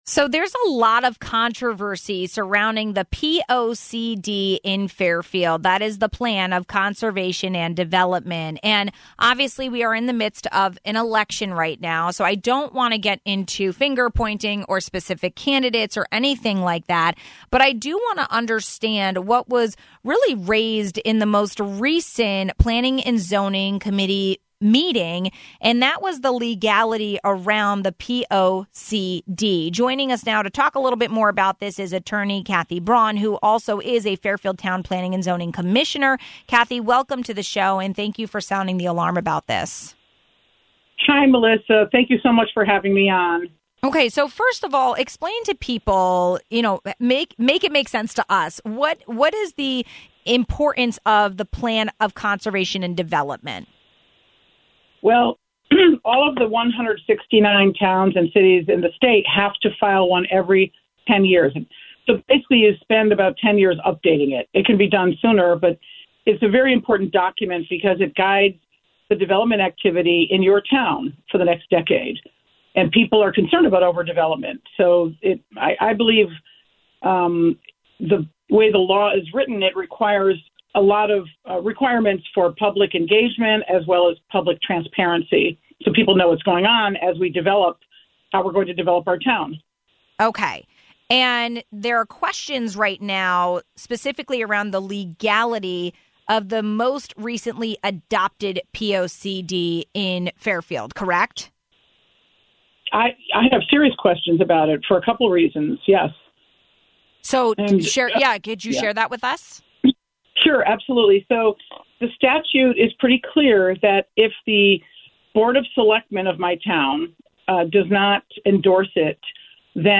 Attorney Kathy Braun is also a Fairfield Town Planning and Zoning commissioner and shared her concerns around this.